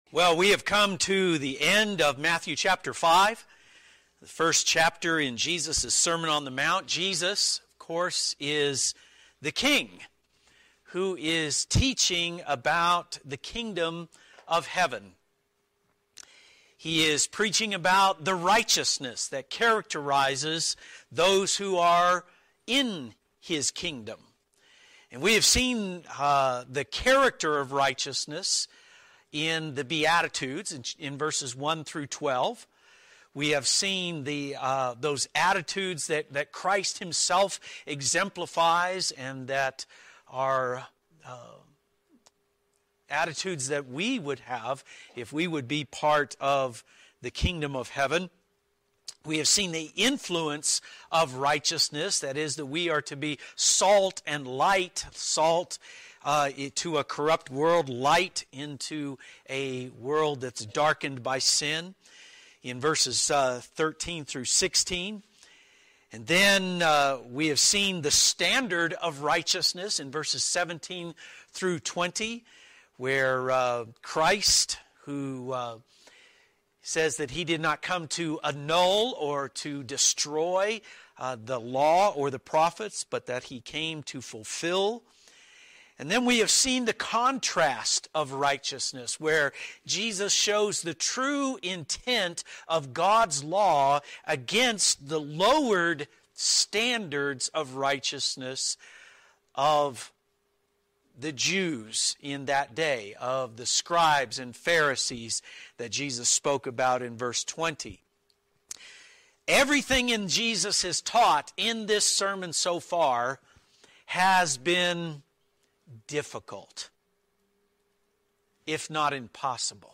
Expository sermon on Matthew 5:43-48.